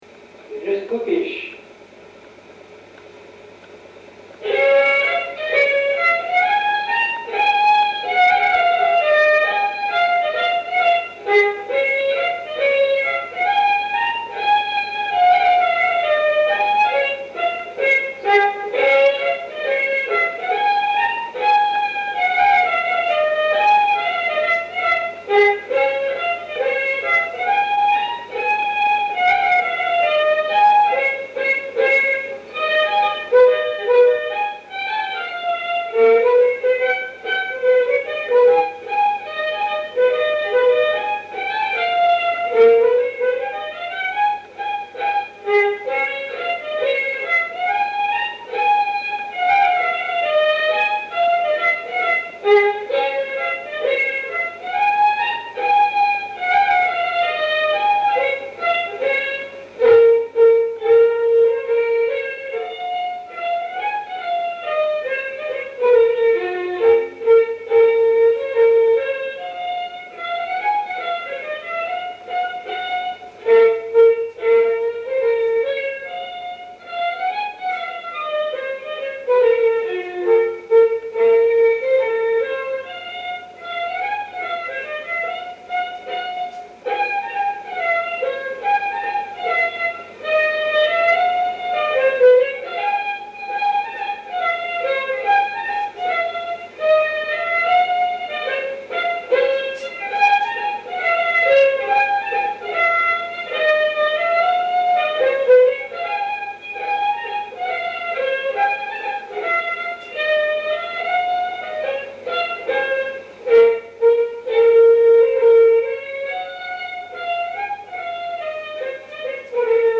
Aire culturelle : Agenais
Lieu : Auterive
Genre : morceau instrumental
Instrument de musique : violon
Danse : scottish